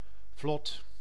Ääntäminen
IPA: /vlɔt/